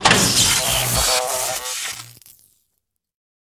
zap.ogg